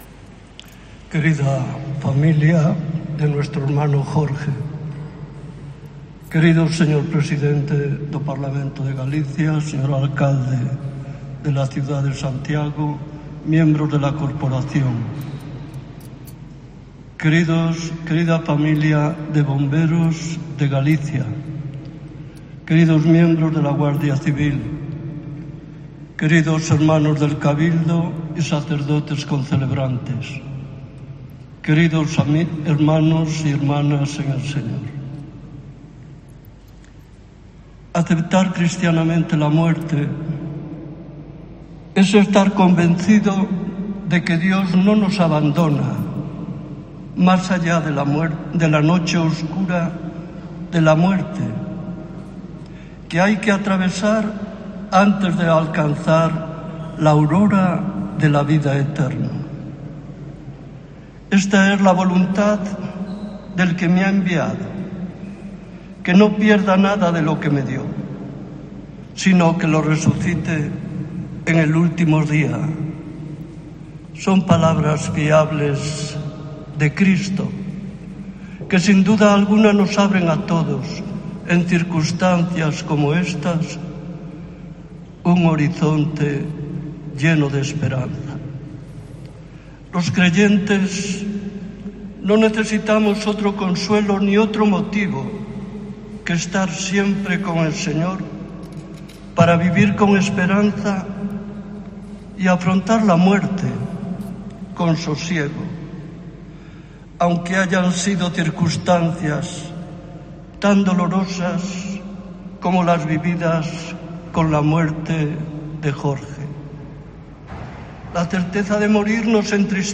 Homilía del arzobispo de Santiago en los funerales por el joven bombero fallecido en acto de servicio